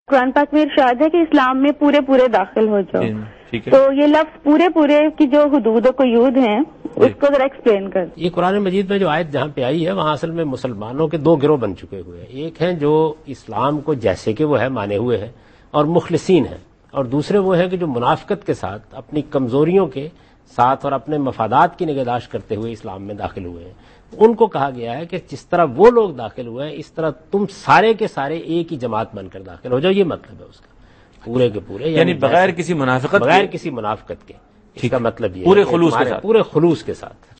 Category: TV Programs / Dunya News / Deen-o-Daanish /
Javed Ahmad Ghamidi answers a question about "Enter Fully into the Folds of Islam" in program Deen o Daanish on Dunya News.